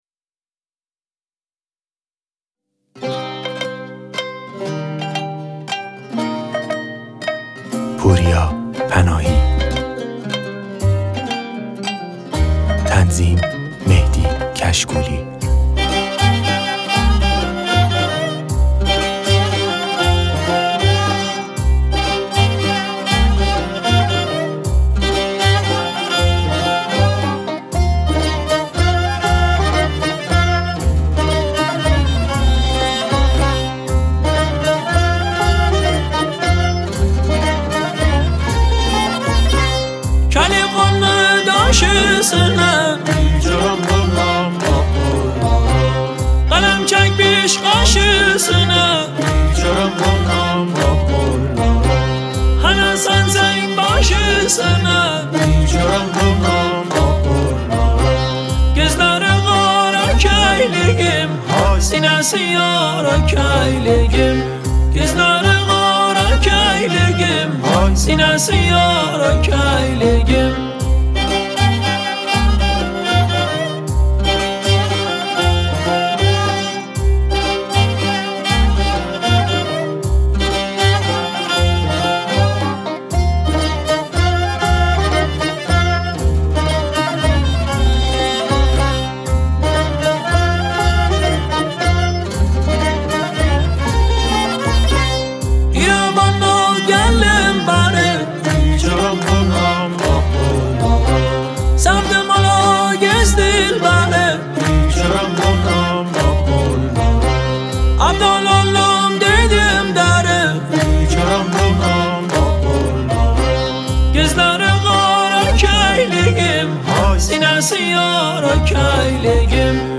آهنگ های ترکی قشقایی
دانلود آهنگ های قشقایی